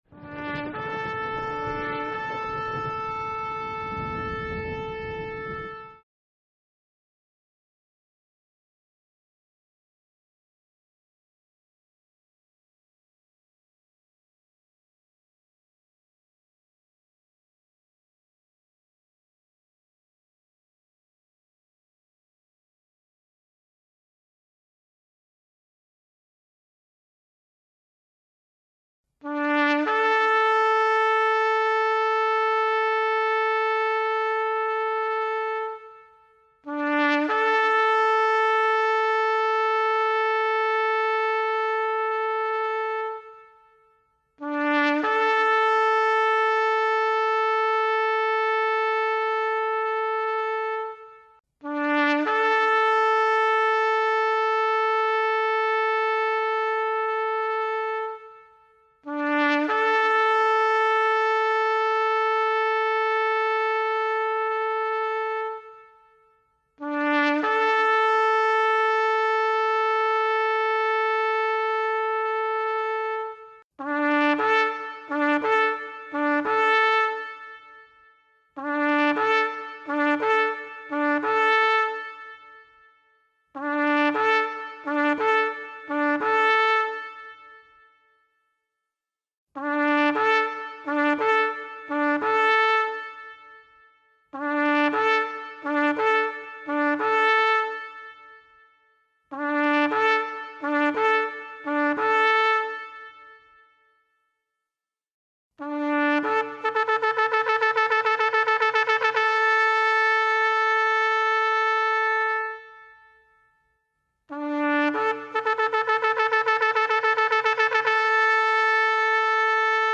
Os-Quatro-Toques-do-Shofar-1.mp3